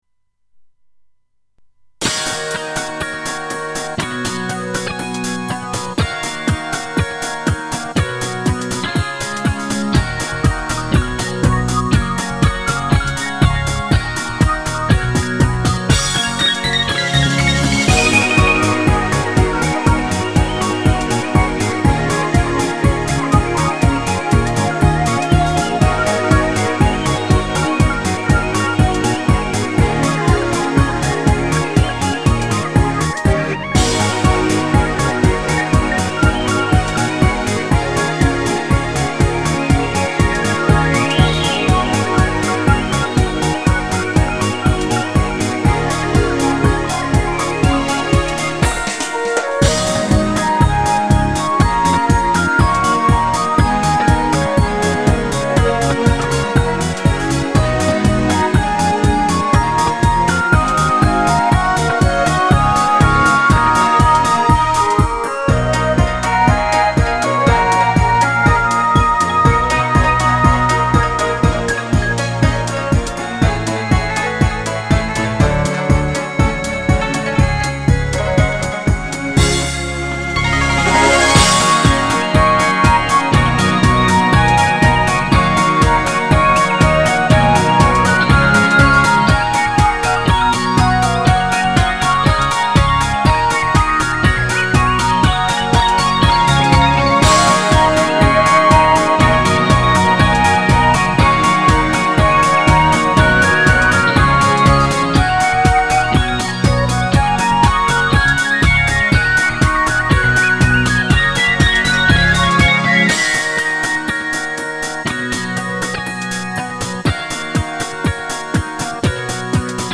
ジャンルはCR花満開です。